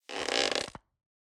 Bow draw 1.wav